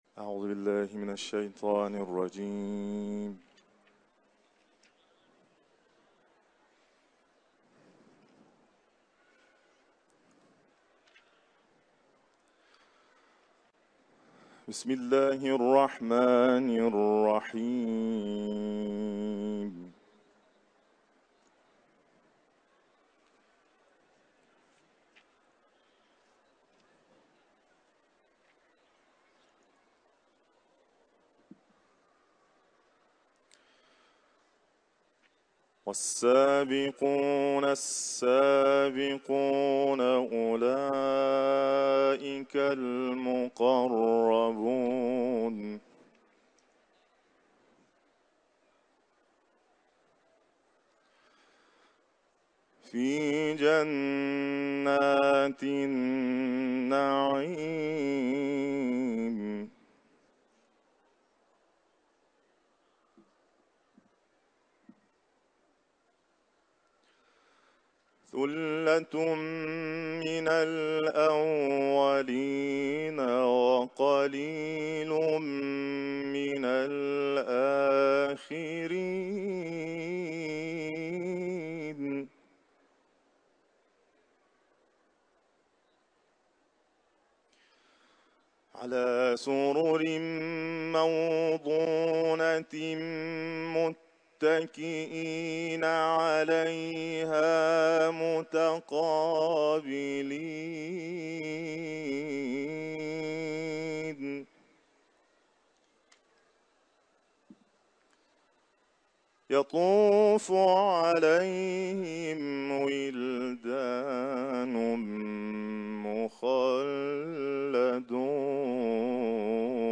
Etiketler: kuran ، tilavet ، İranlı kâri